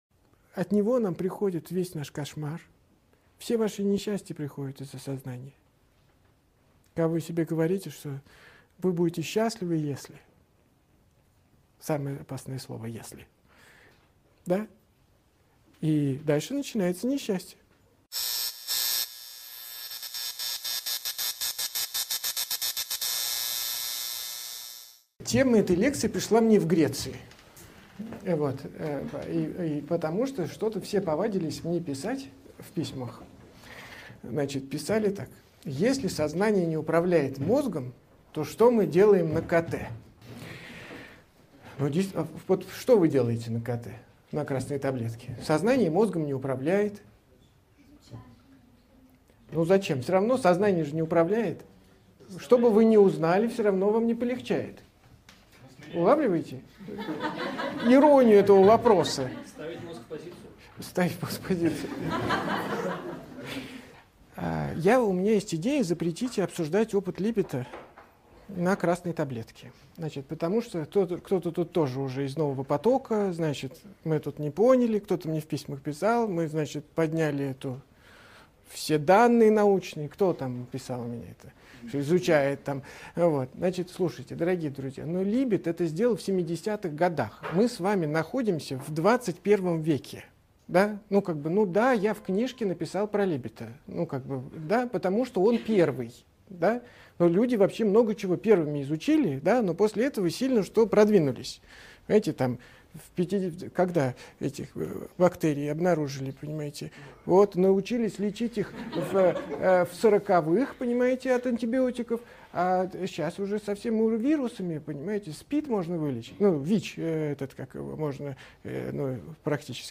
Аудиокнига Усилие воли.
Автор Андрей Курпатов Читает аудиокнигу Андрей Курпатов.